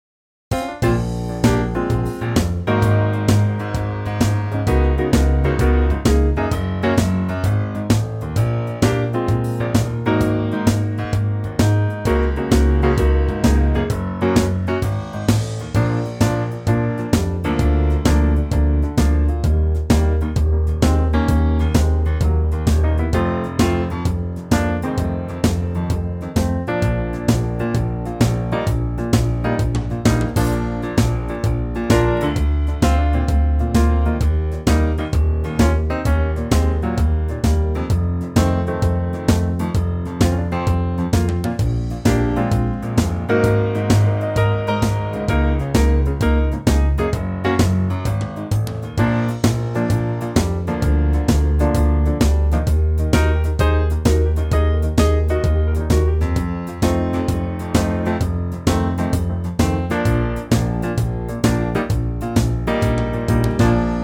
key - Bb - vocal range - Bb to D
-Unique Backing Track Downloads